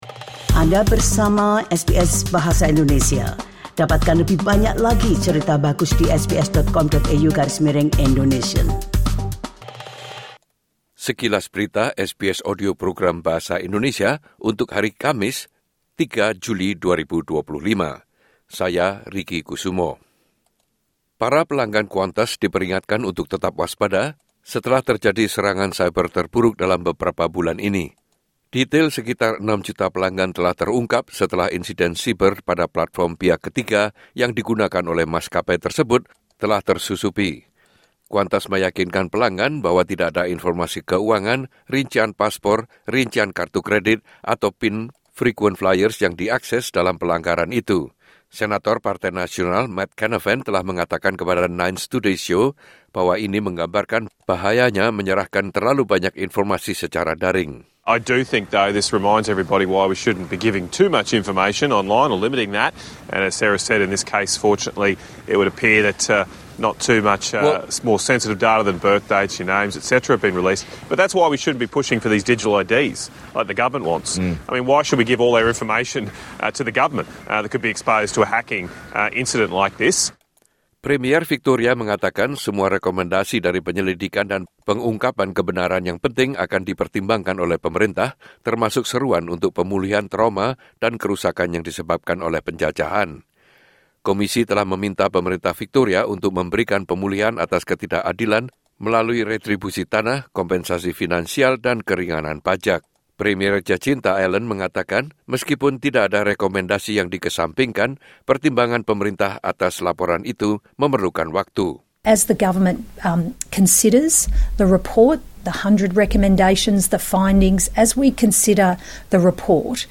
Berita terkini SBS Audio Program Bahasa Indonesia – 3 Juli 2025